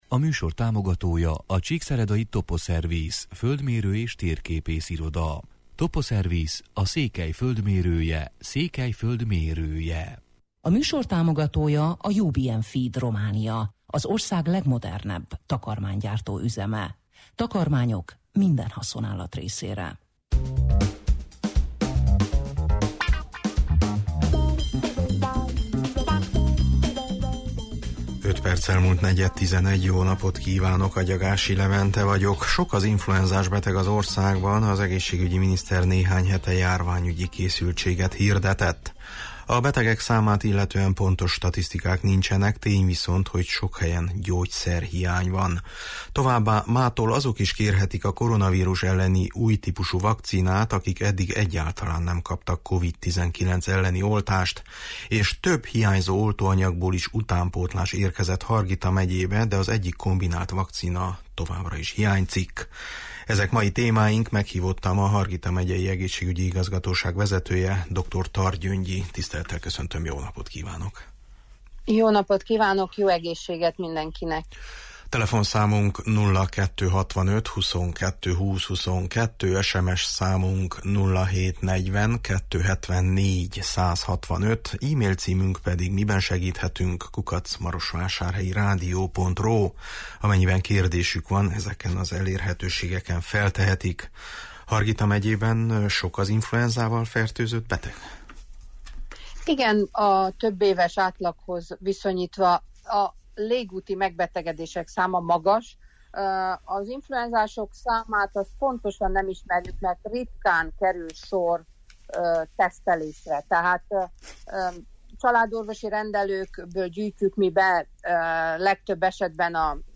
Meghívottam a Hargita Megyei Egészségügyi Igazgatóság vezetője, Dr. Tar Gyöngyi.